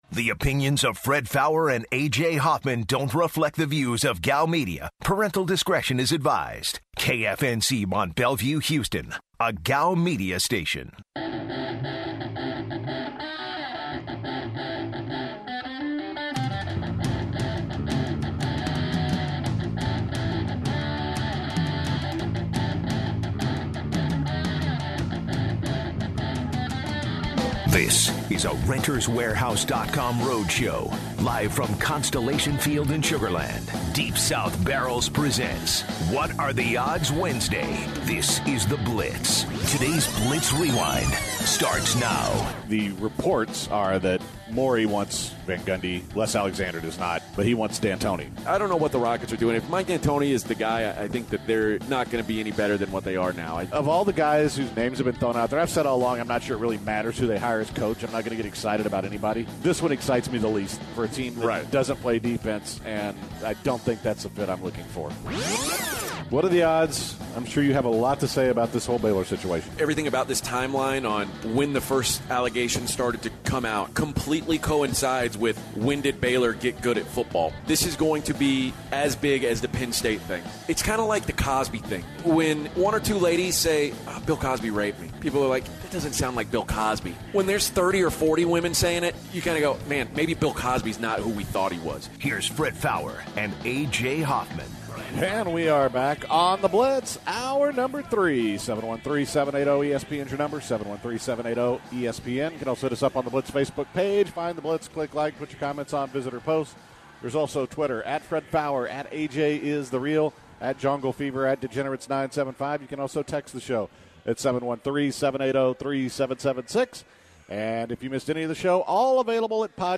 live from Constellation Field wrapping up The Blitz